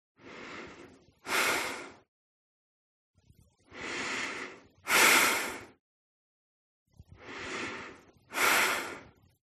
Звуки дыхания, храпа
Тяжелое дыхание женщины